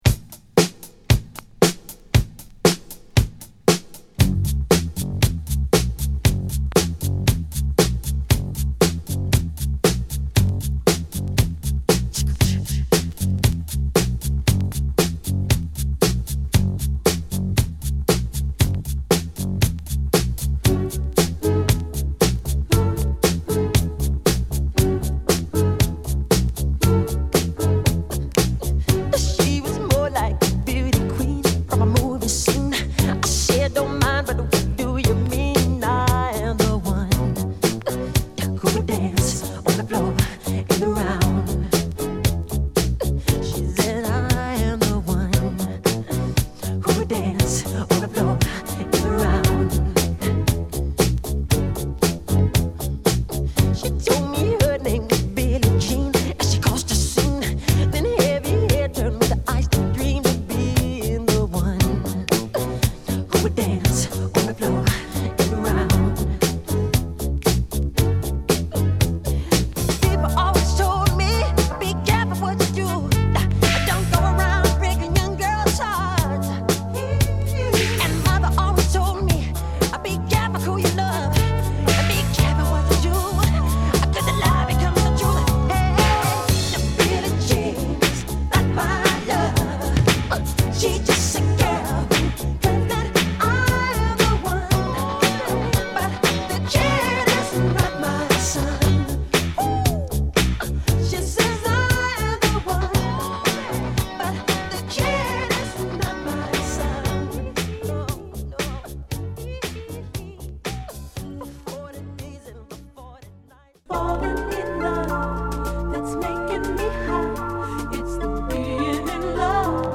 特徴的なベースラインが引っ張るモンク無しのダンスチューン！！